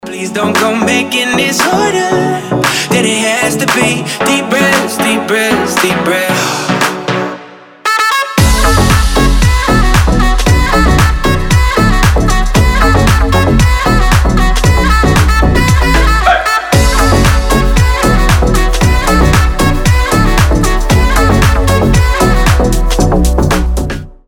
• Качество: 320, Stereo
deep house
заводные
house
труба